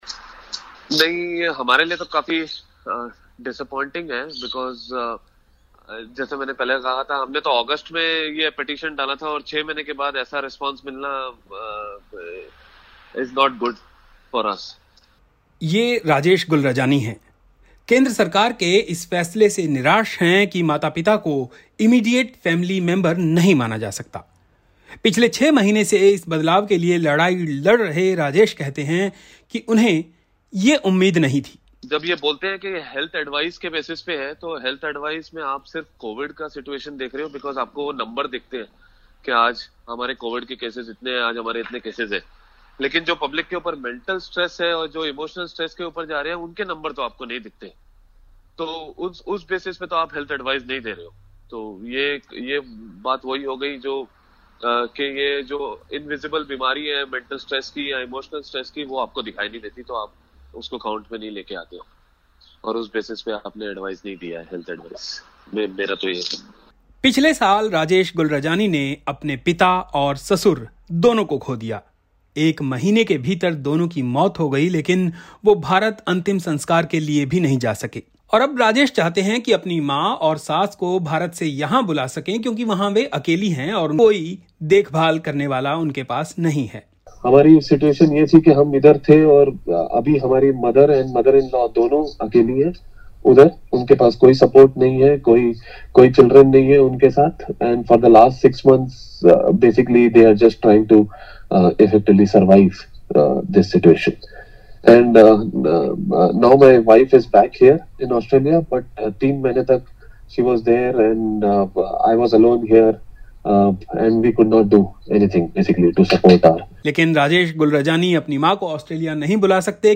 Listen to the report in Hindi: